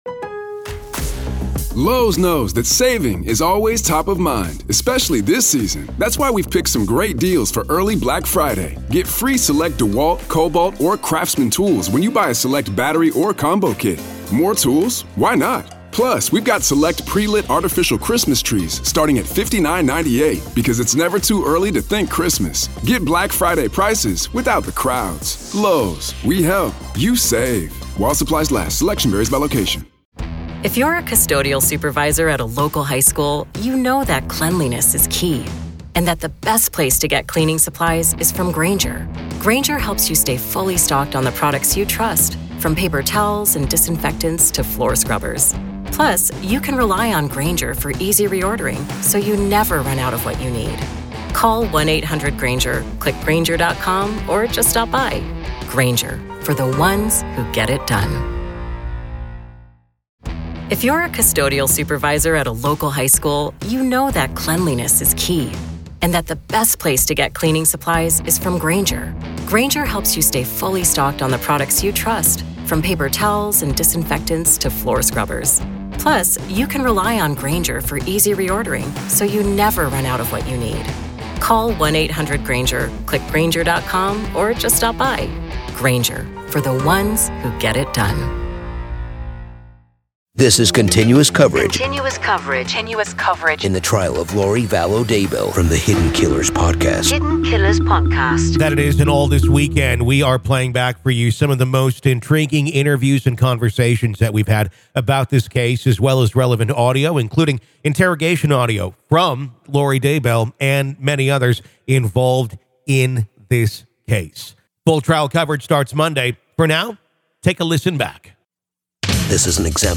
Apr 10, 2023, 01:00 AM Headliner Embed Embed code See more options Share Facebook X Subscribe Join us this weekend on our riveting podcast as we journey through the most captivating interviews and enthralling audio snippets that delve into the enigmatic case against Lori Vallow Daybell.